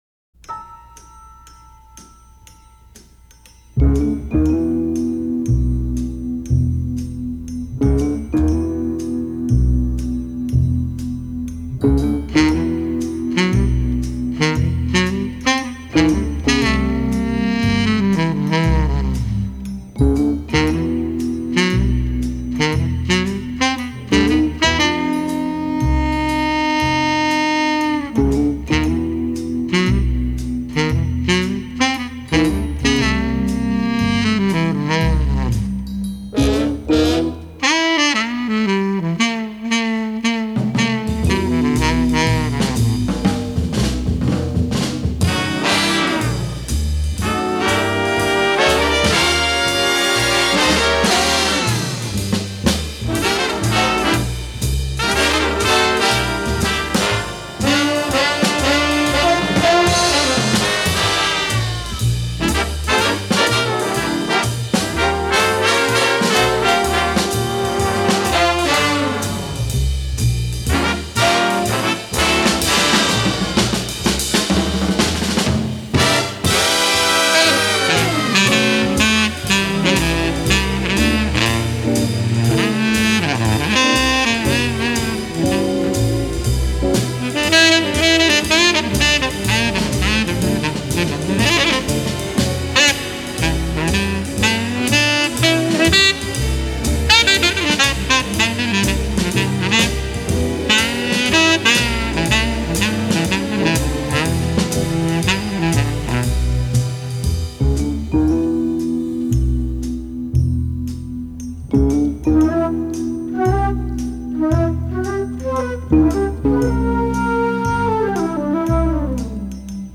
Soundtrack / Jazz / Lounge